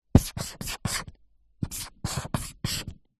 Звуки маркеров
Маркеры альтернативный вариант